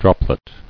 [drop·let]